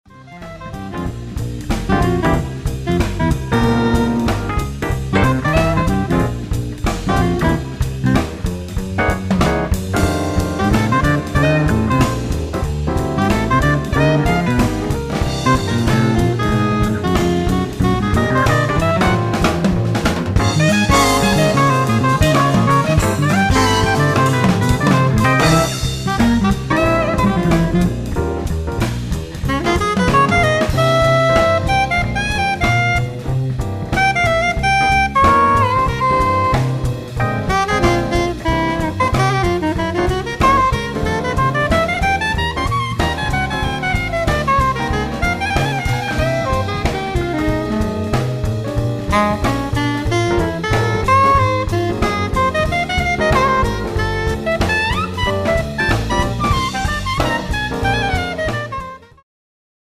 acoustic, electric bass, electric guitar, vocals
soprano, tenor sax
piano, keyboards
drums